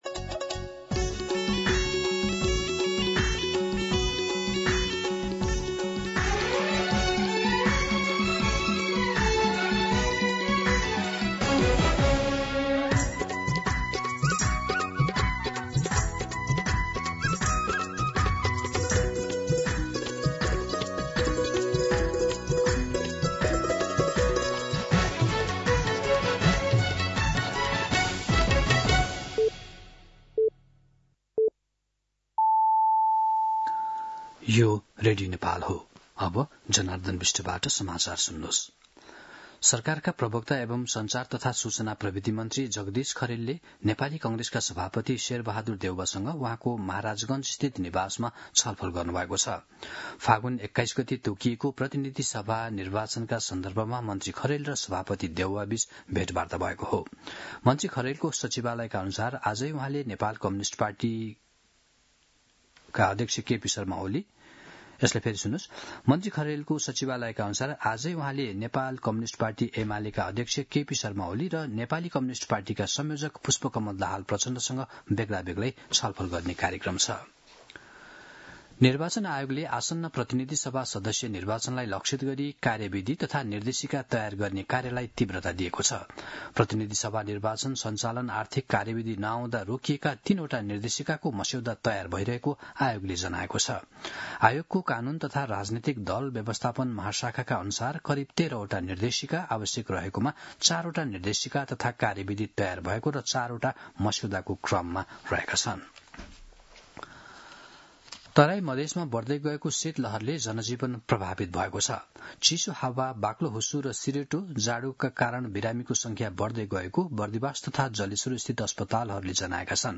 मध्यान्ह १२ बजेको नेपाली समाचार : ११ पुष , २०८२